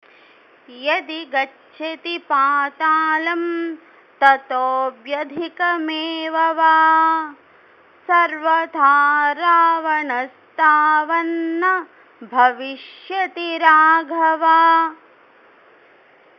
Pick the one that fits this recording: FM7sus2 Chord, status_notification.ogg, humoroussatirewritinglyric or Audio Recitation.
Audio Recitation